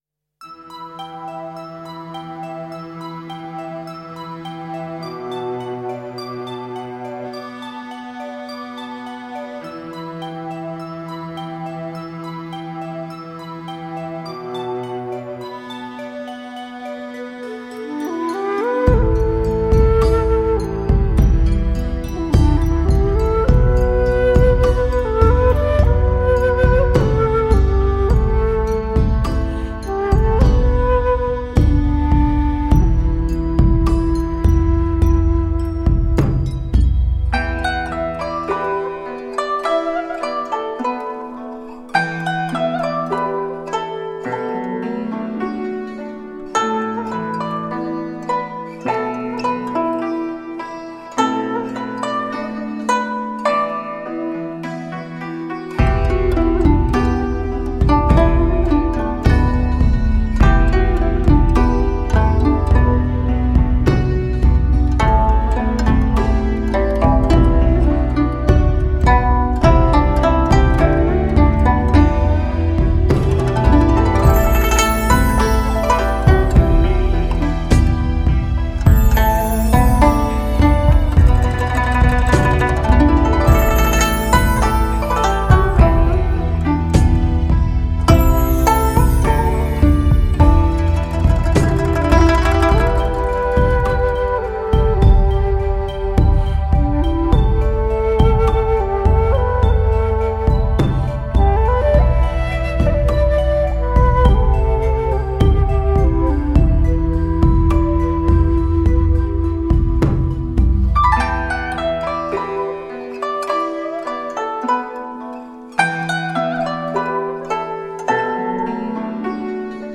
此张示范碟采用K2 HD做Mastering，1:1直刻母盘，音质绝佳，
可作为测试发烧音响专用的CD唱片。
古筝·笛